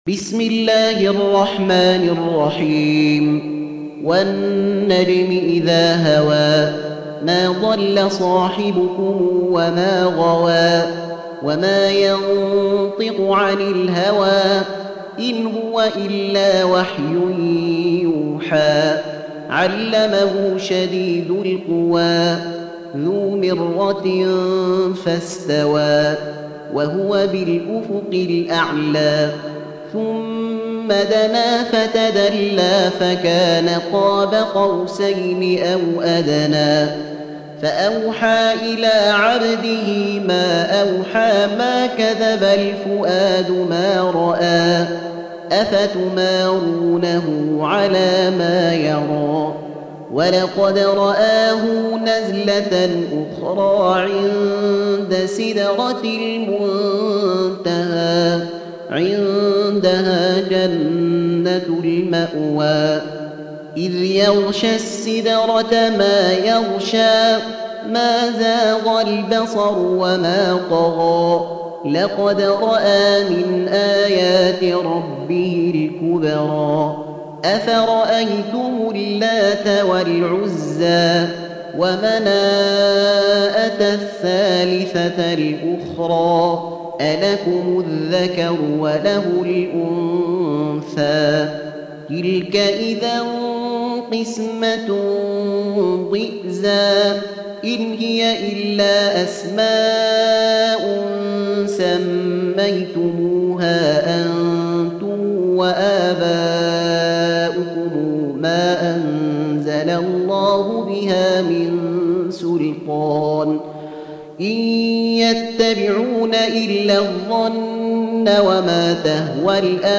Surah An-Najm سورة النجم Audio Quran Tarteel Recitation
حفص عن عاصم Hafs for Assem